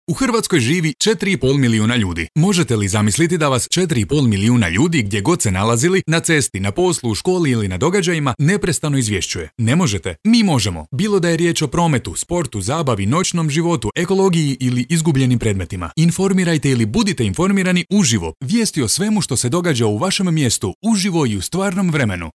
Croatian voice over talent with positive and pleasant voice and a neutral accent with over 10 years of experience in broadcasting.
Sprechprobe: Werbung (Muttersprache):